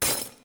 terumet_break.1.ogg